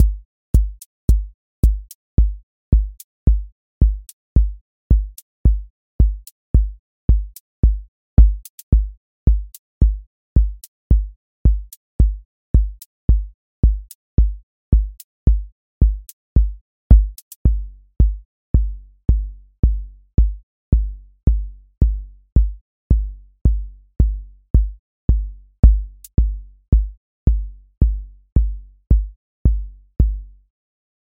Four Floor Drive QA Listening Test house Template: four_on_floor April 17, 2026 ← Back to all listening tests Audio Four Floor Drive
four on floor
voice_kick_808 voice_hat_rimshot voice_sub_pulse